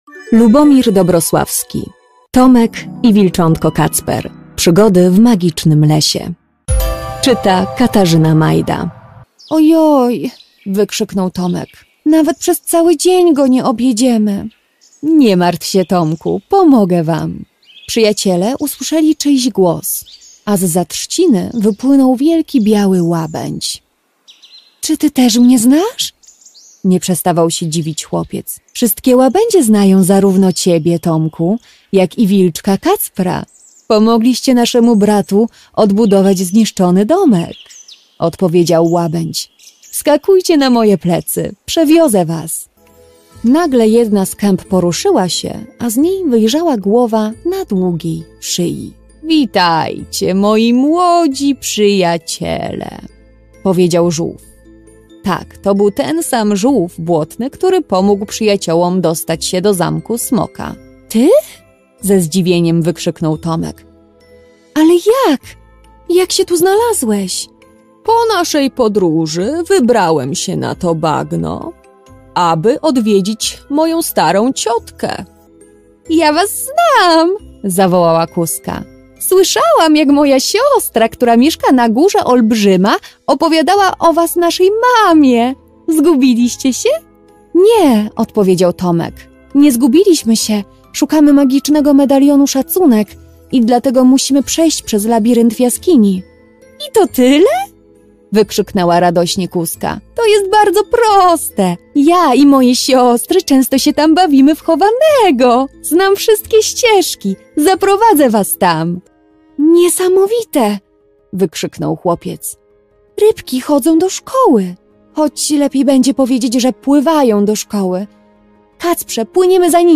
Kommerziell, Natürlich, Freundlich, Warm, Sanft
Persönlichkeiten